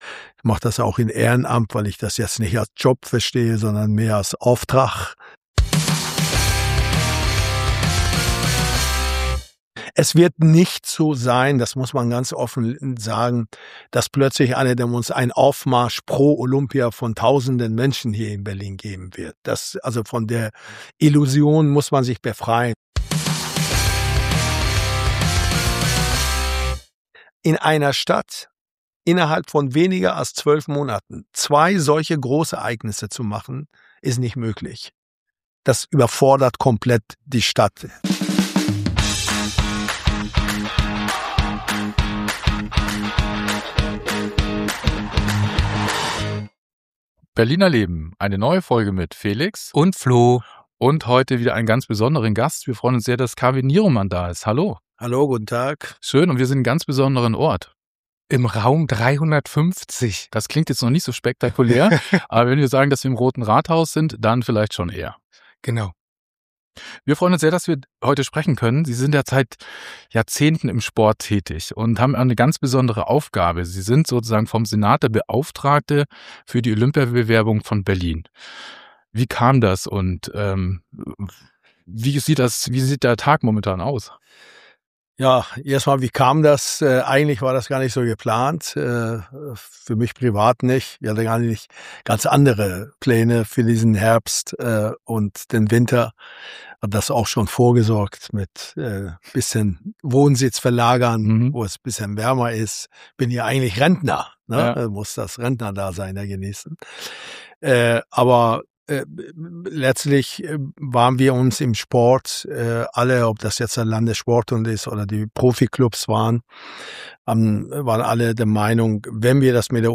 Ein Gespräch im Roten Rathaus, genauer im Raum 350.